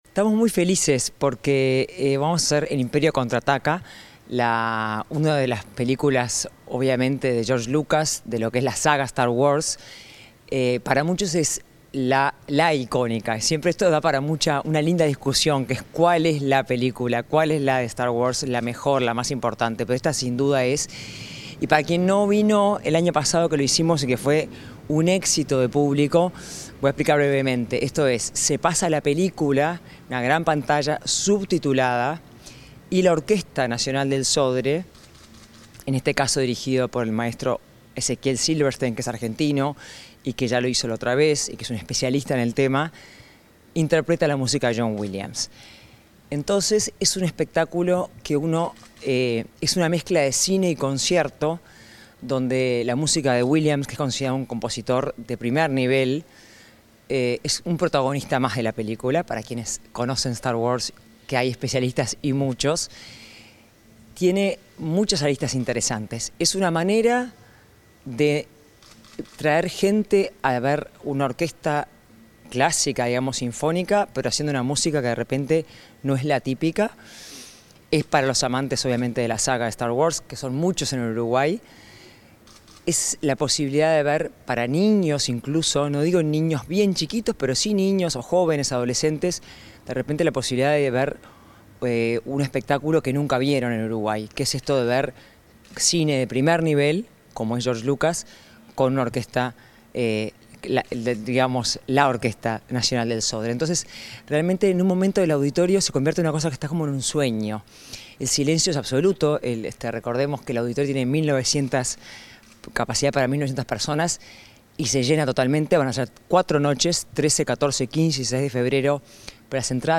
Entrevista a la presidenta del Sodre, Adela Dubra